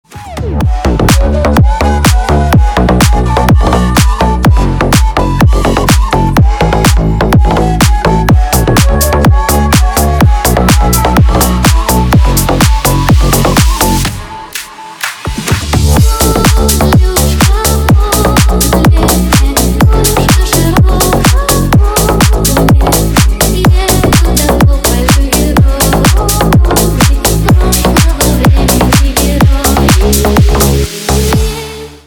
громкая музыка для мобильного